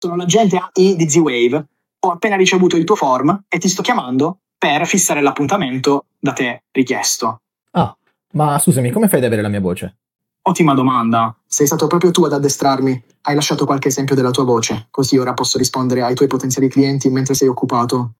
AI VOICE AGENTS e RECEPTIONIST
La nostra AI vocale risponde al primo squillo, sempre.
I nostri agenti vocali gestiscono chiamate in entrata e uscita h24 con un tono umano indistinguibile, trasformando il telefono da problema logistico a macchina da conversione.
voce-agente-ai-demo.mp3